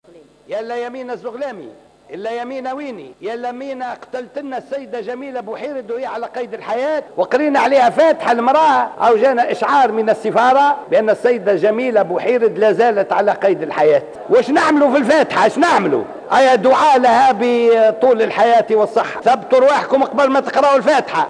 Les députés de l'Assemblée des représentants du peuple (ARP) ont récité, ce mardi 10 novembre 2015, Al-Fatiha en hommage à la grande militante algérienne Djamila Bouhired.